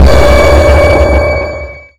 sonarTailWaterCloseShuttle2.ogg